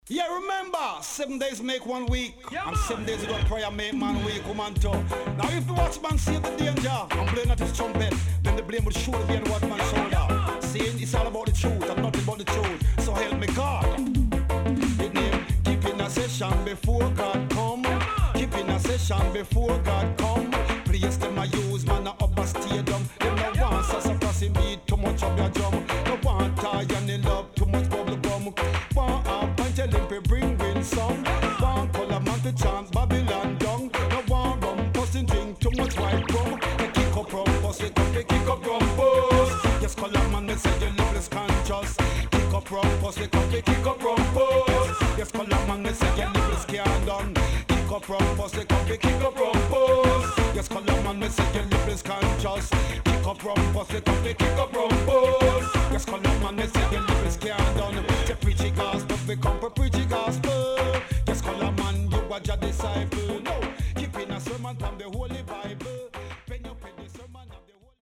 HOME > LP [DANCEHALL]
SIDE B:少しチリノイズ、プチノイズ入ります。